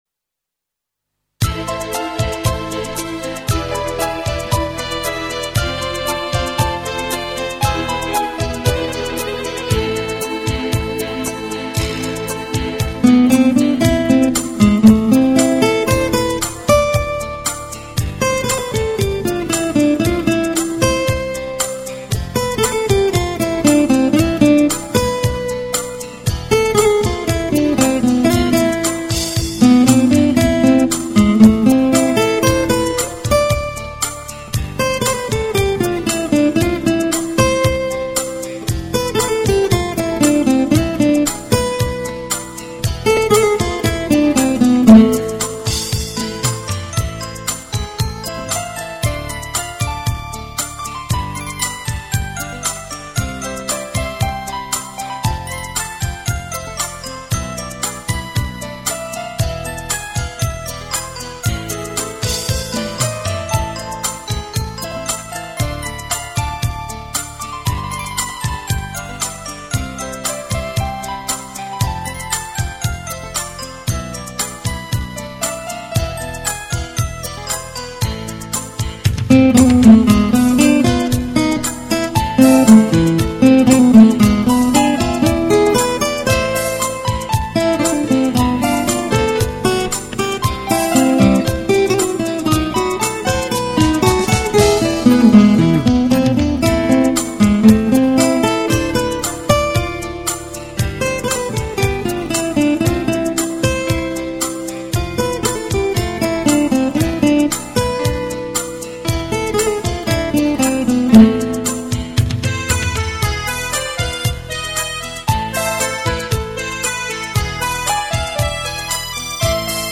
0248-吉他名曲托卡塔.mp3